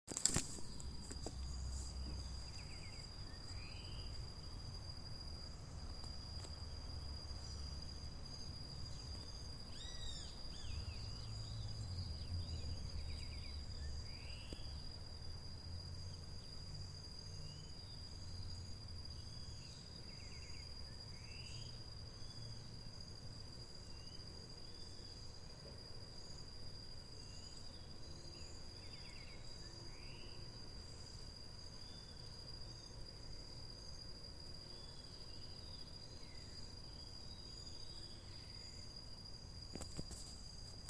Pepitero Gris (Saltator coerulescens)
Nombre en inglés: Bluish-grey Saltator
Localización detallada: Parque Guillermina - Av Mate De Luna 4100
Condición: Silvestre
Certeza: Vocalización Grabada
Pepitero-gris.mp3